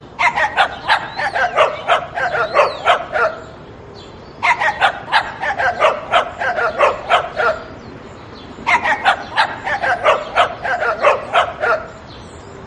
SMS hangok .
Dogs_Vs_Nokia
Dogs_Vs_Nokia.mp3